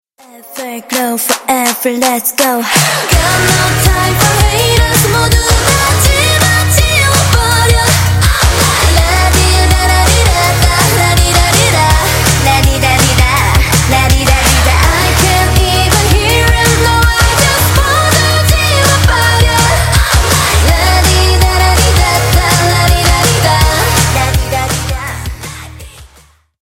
Скачать припев песни